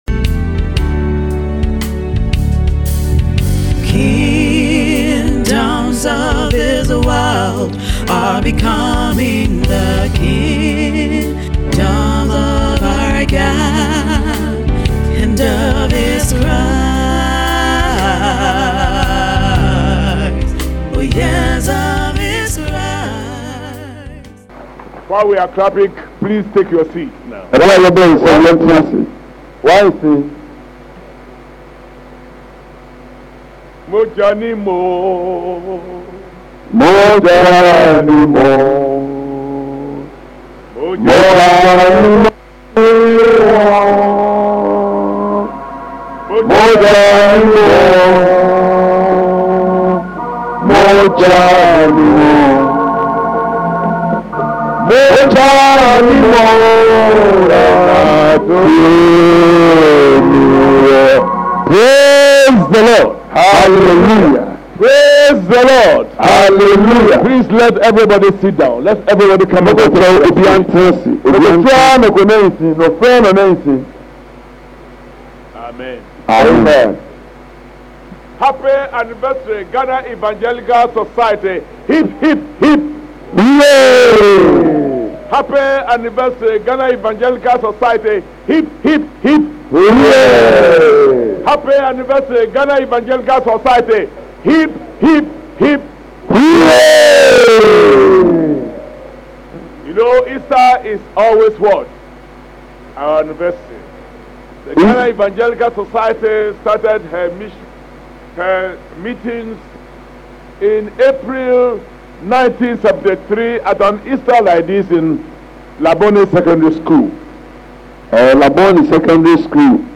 2025 Behold the King of Glory Pt 1 Series: Audio Sermon